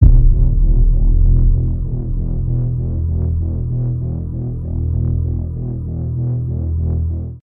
Звуки из фильмов ужасов
На этой странице представлены устрашающие звуки из фильмов ужасов для монтажа видео.
11. Хит для трейлера к фильму ужасов
Данные звуки отлично подойдут для придания вашему видео атмосферы жути, используйте их в своих проектах для создания всяческих сцен устрашения.